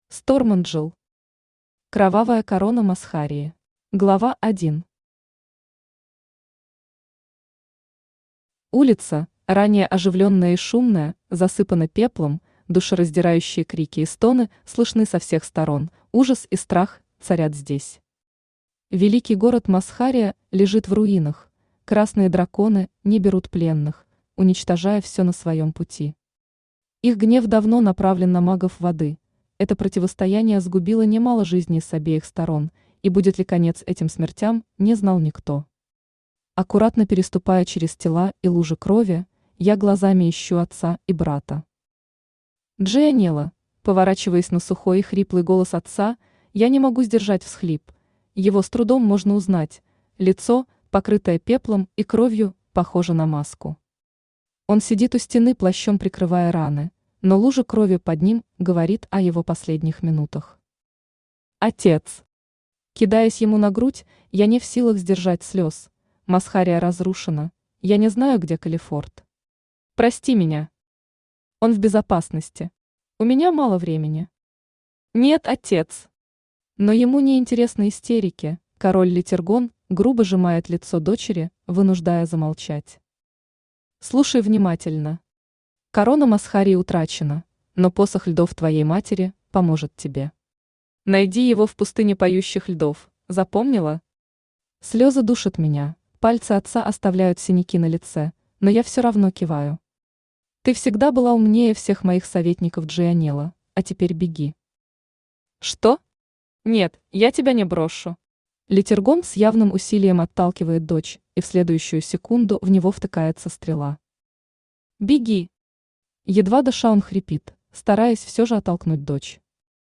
Аудиокнига Кровавая корона Масхарии | Библиотека аудиокниг
Aудиокнига Кровавая корона Масхарии Автор Stormangel Читает аудиокнигу Авточтец ЛитРес.